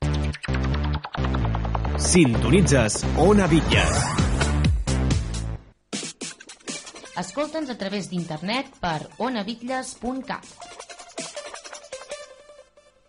9ade2fe01441ed9c9c62a221b65e9b1b5a7578d4.mp3 Títol Ona Bitlles Emissora Ona Bitlles Titularitat Pública municipal Descripció Indicatiu de l'emissora i adreça de l'emissora a Internet.